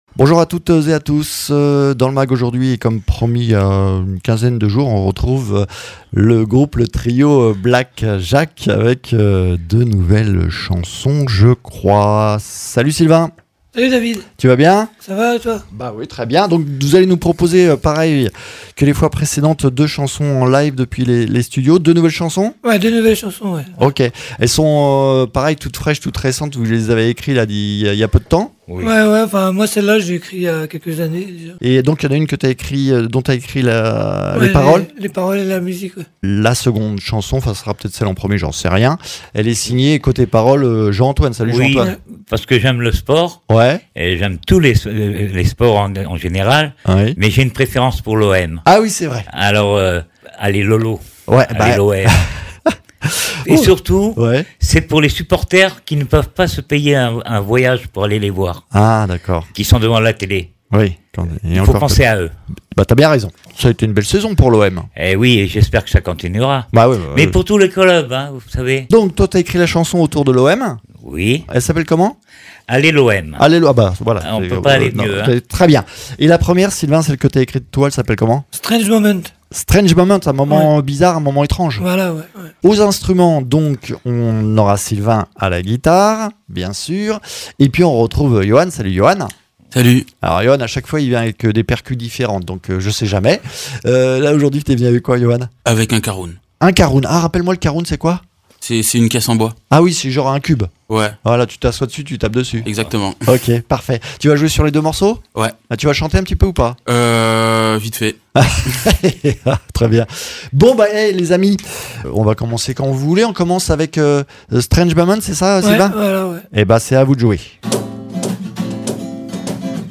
3e session du groupe Black Jacques avec 2 nouvelles chansons en live dans nos studios et puis un nouvel épisode ’’dans les coulisses du cap mômes’’